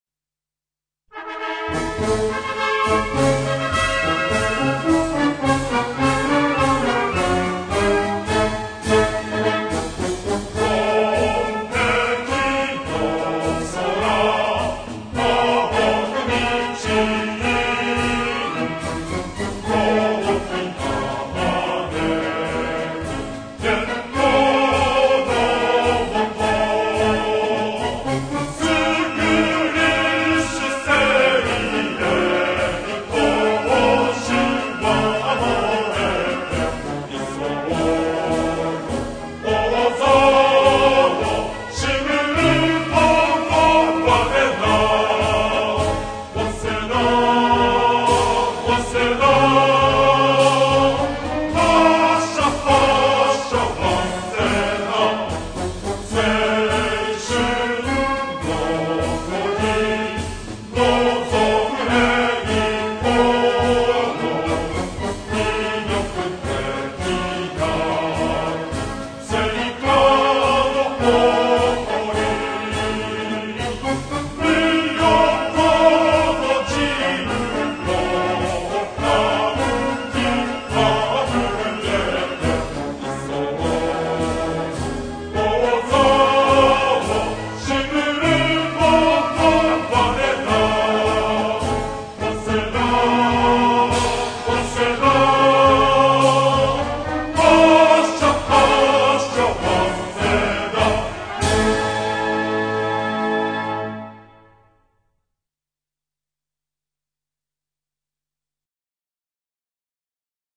waseda_univ_cheering_1.mp3